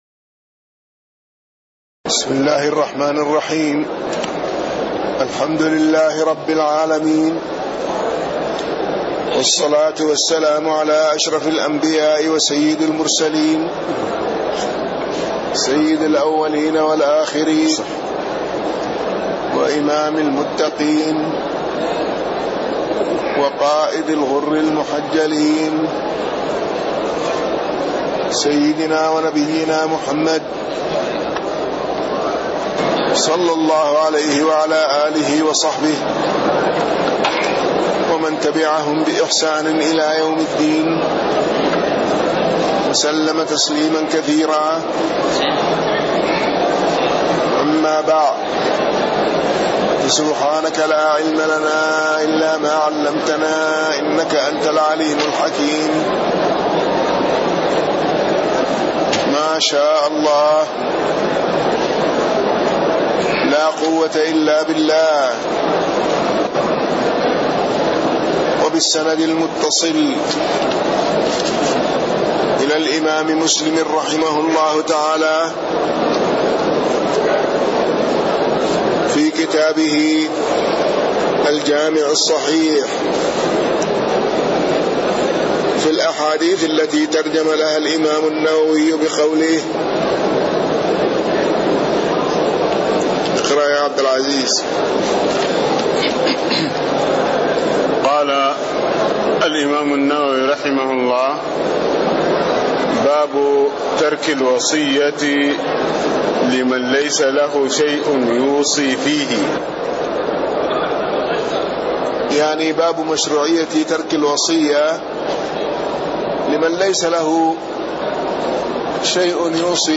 تاريخ النشر ٢٤ ربيع الثاني ١٤٣٥ هـ المكان: المسجد النبوي الشيخ